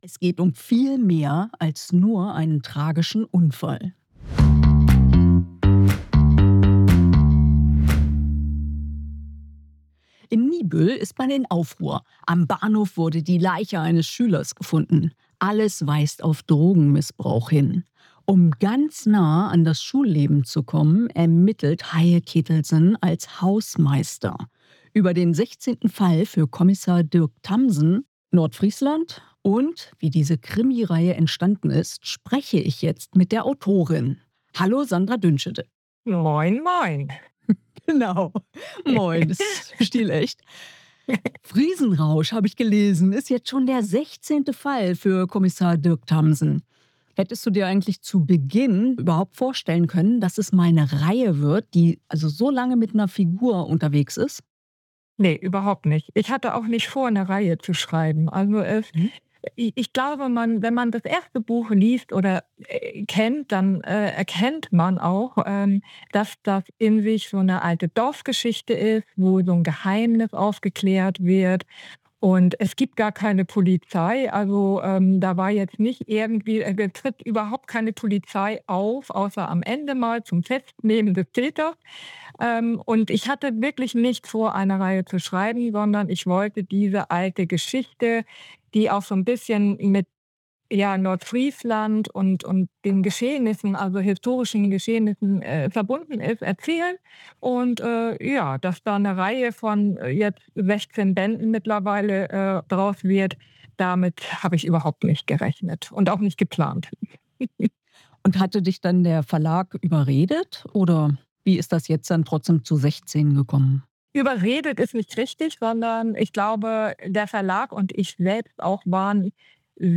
In jedem Fall bietet der Krimi genügend Stoff für ein spannendes Interview.